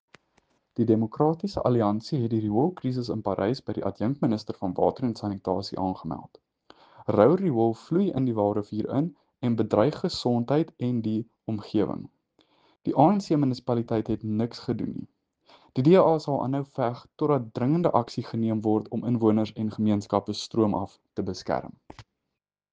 Afrikaans soundbites by Cllr JP de Villiers and